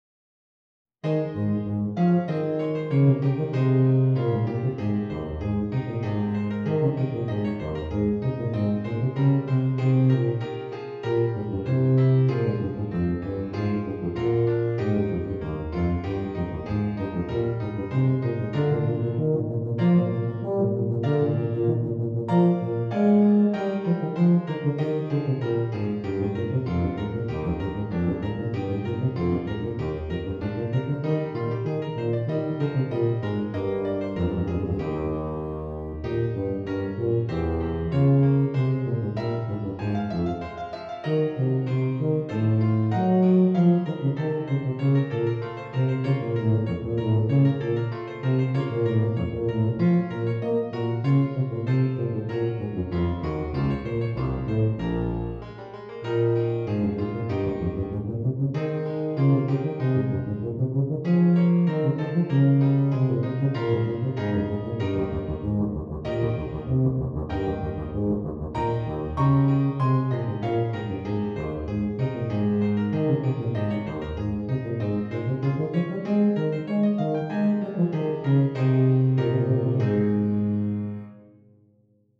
Tuba and Keyboard